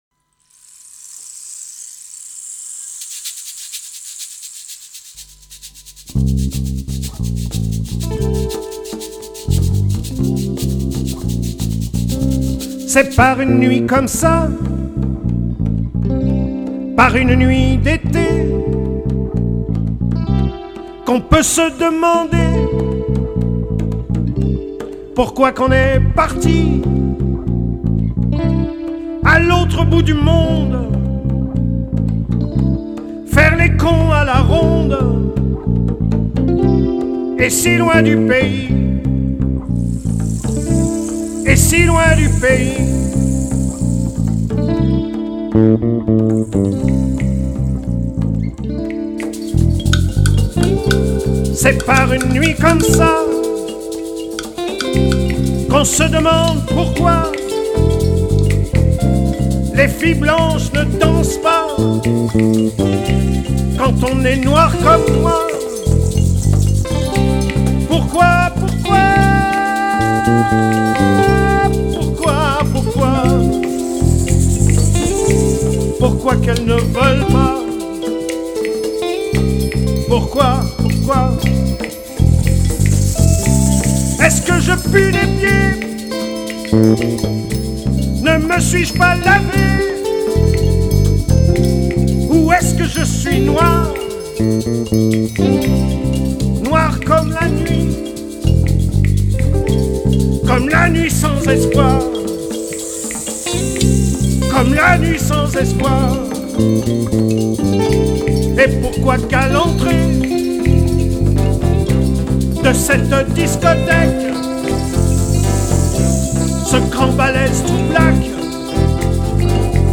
La rumba